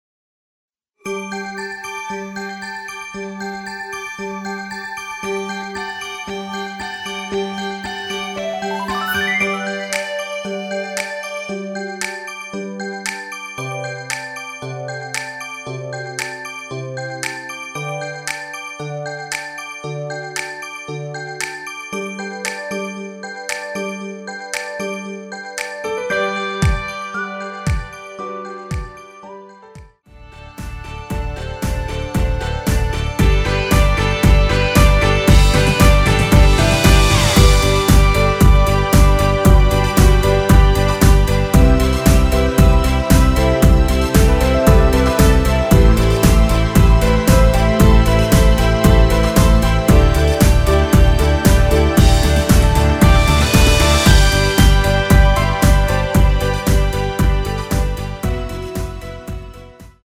엔딩이 페이드 아웃이라 노래 부르기 좋게 엔딩 만들었습니다.
◈ 곡명 옆 (-1)은 반음 내림, (+1)은 반음 올림 입니다.
앞부분30초, 뒷부분30초씩 편집해서 올려 드리고 있습니다.
중간에 음이 끈어지고 다시 나오는 이유는